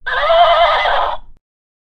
horse